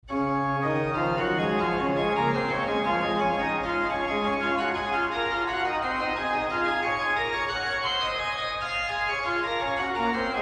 Der Klang der Ehrlich-Orgel in der Stadtkirche hat seine Wurzeln im mainfränkischen Orgelbau der Barockzeit. In seiner Farbigkeit wirkt er auf heutige Ohren meist auf das erste eher ein wenig fremd und archaisch.